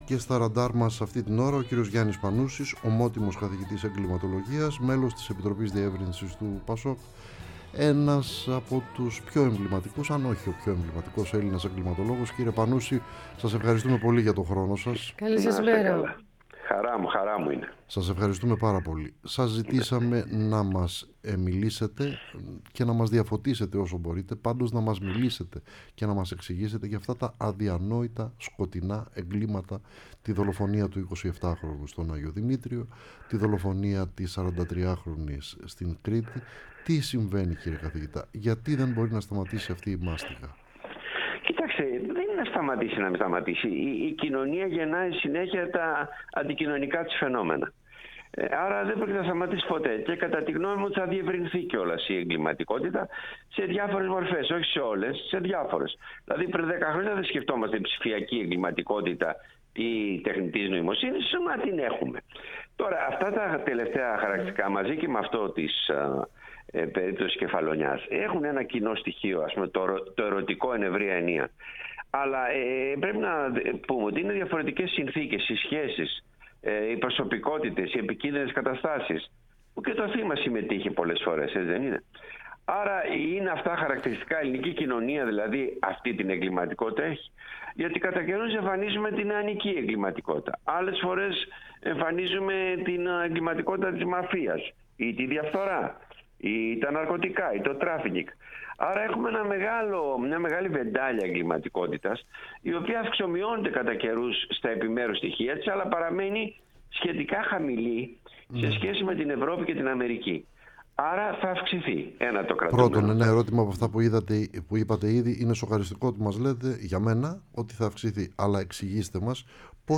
Ο Γιάννης Πανούσης, Ομότιμος Καθηγητής Εγκληματολογίας-Μέλος Επιτροπής Διεύρυνσης του ΠΑΣΟΚ, μίλησε στην εκπομπή “Ραντάρ”